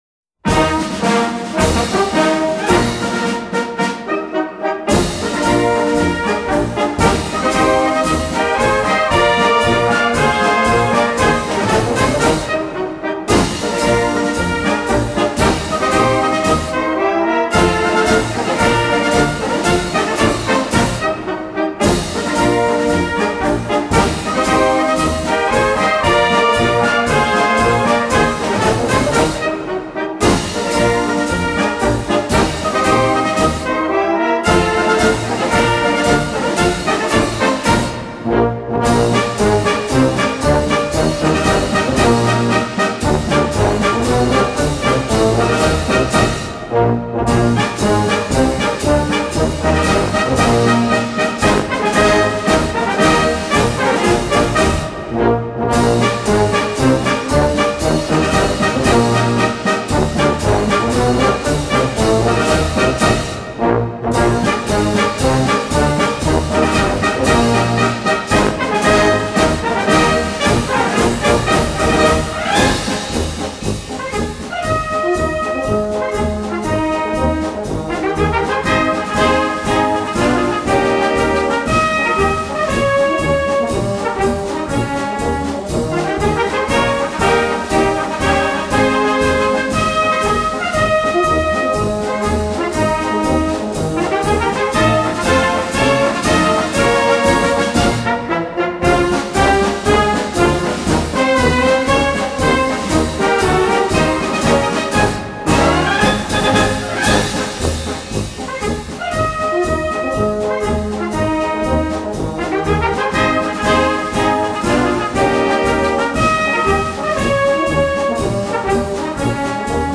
Die Darbietung des flotten Marsches beschwingte auch anwesende Vertreter des Streitkräfteführungskommando-Stabes der Standorte Graz und Salzburg sowie Abordnungen der in Niederösterreich stationierten Verbände.